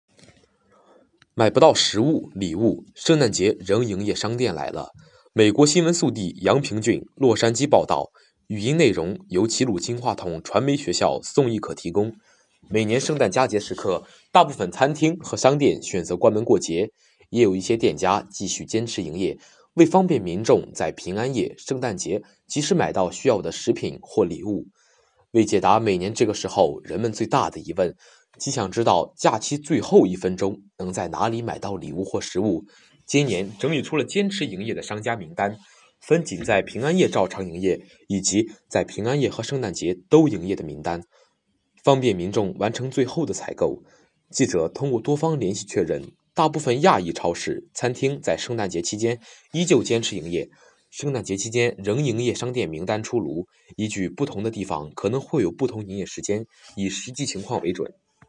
【听新闻学播音】买不到食物、礼物？ 圣诞节仍营业商店来了